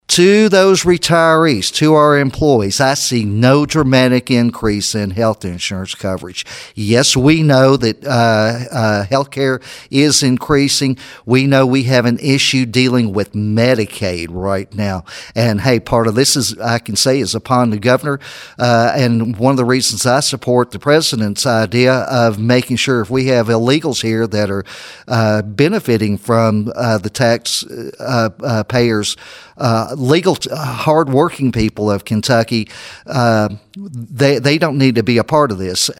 Third District State Senator Craig Richardson was joined by State Representatives Mary Beth Imes, Walker Thomas, and Myron Dossett during the Your News Edge Legislative Update program Saturday that was recorded at the H&R Agri-Power Pancake Day.